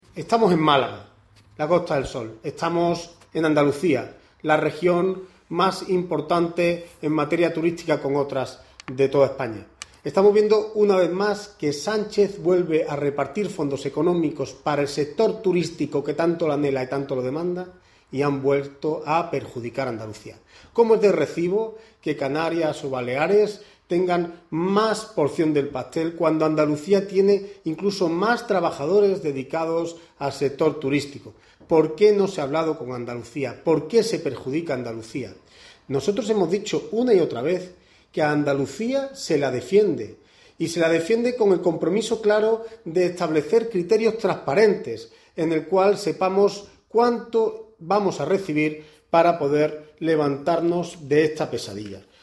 En rueda de prensa en Málaga, Carmona ha recordado que Andalucía y la Costa del Sol son zonas de las más importantes de España en materia turística y lamentó que Baleares y Canarias “tengan más parte del pastel de las ayudas” cuando en Andalucía “hay muchísimos más trabajadores en este sector”.